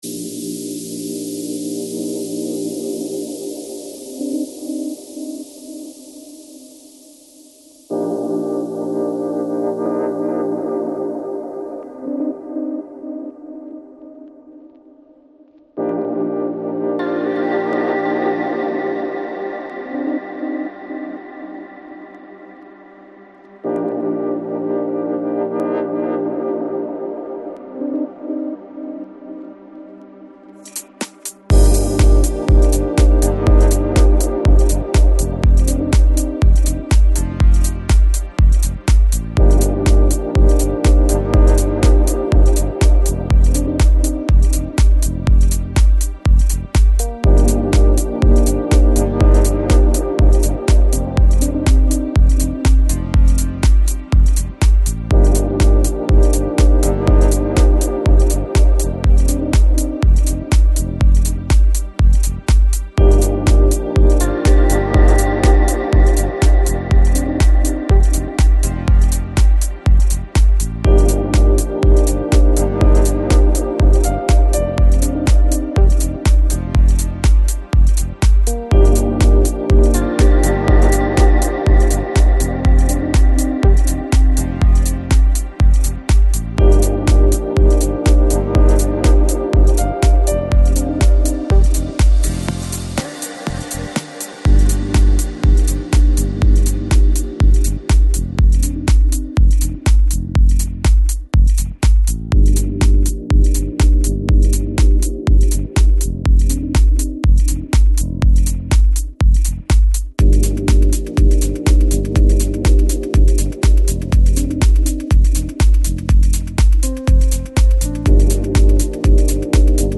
Electronic, Chill Out, Lounge, Downtempo Год издания